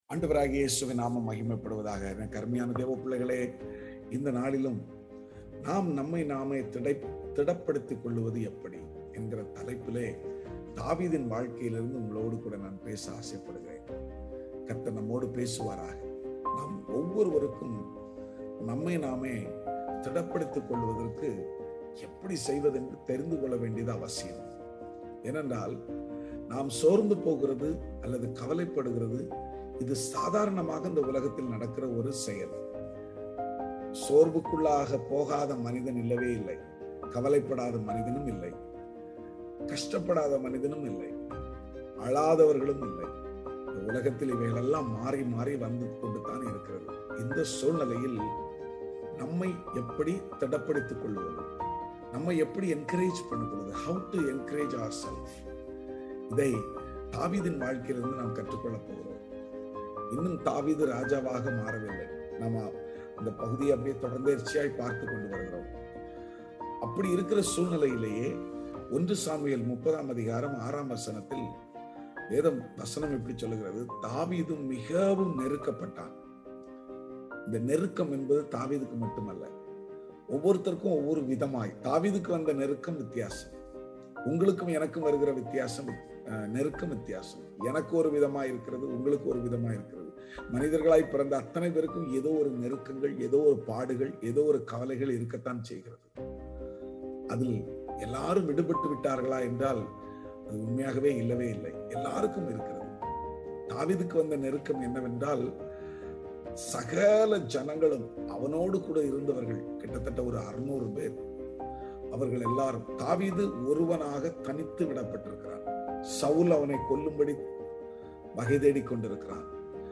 Self Encouragement - Morning Devotion 22 July 2022